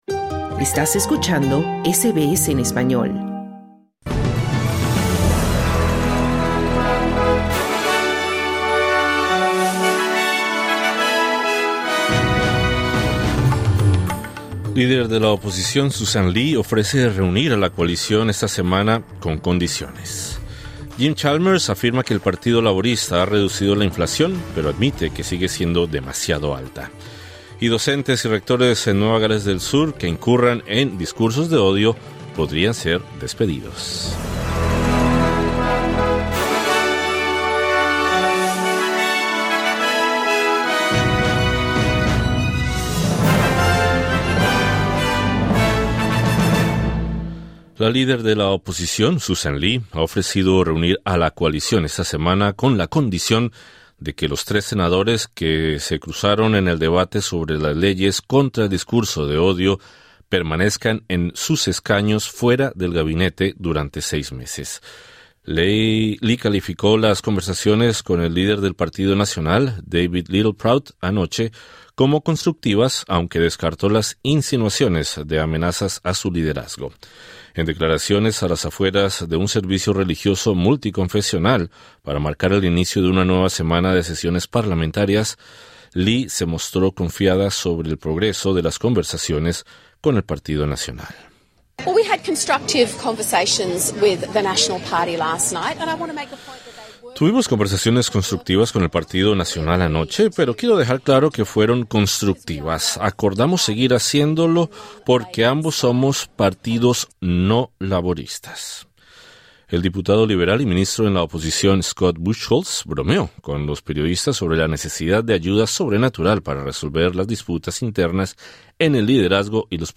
Sussan Ley, ha ofrecido reunir a la Coalición con la condición de que los tres senadores nacionalistas en el centro de la controversia permanezcan fuera del gabinete. Escucha el resumen informativo de este martes 3 de febrero 2026.